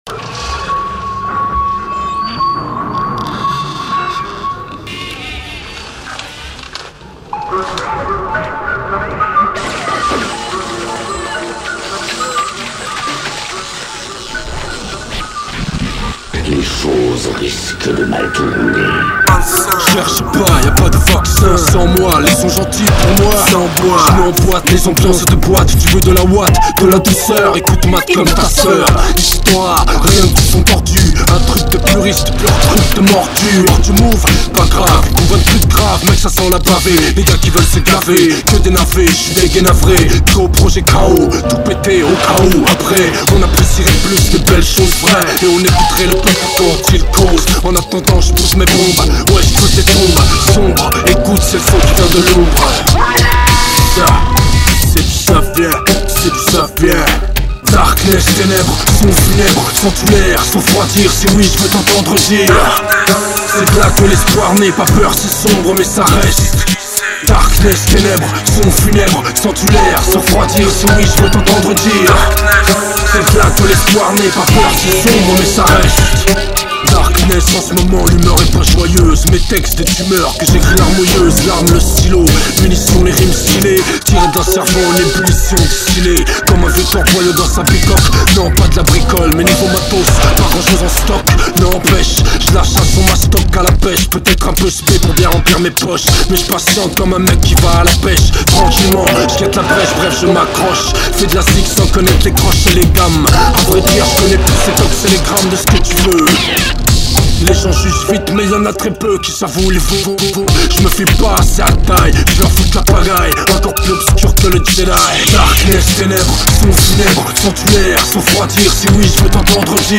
les cuts et autres skratchs expérimentaux
Sorry, récupérer y'as peu dans les méandres du plus profond d'internet, veuillez excuser la piètre qualité de l'audio